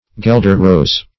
Gelder-rose \Gel"der-rose\, n.